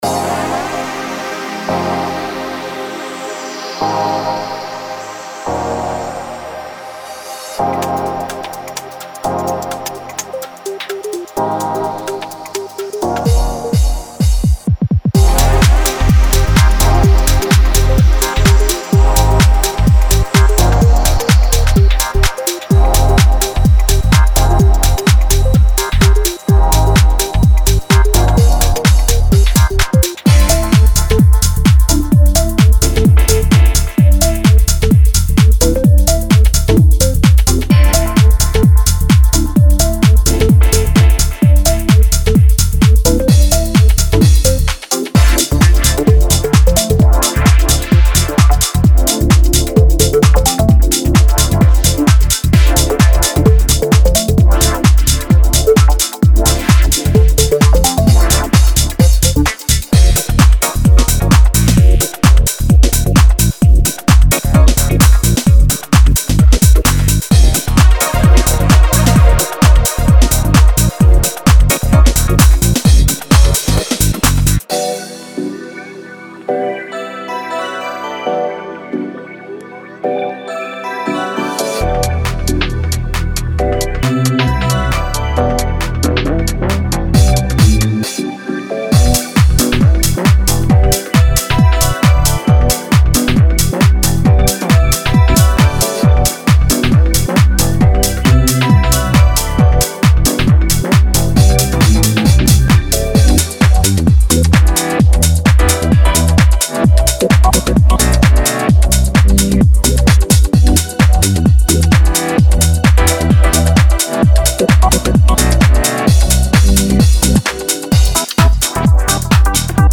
このパックは、パンチの効いたリズムとムーディーなコード進行がぶつかり合う構造を基盤としています。
深みと動き、音響的な実験性を求めるプロデューサーのために設計されたシンセと鍵盤が特徴です。
デモサウンドはコチラ↓
Genre:Tech House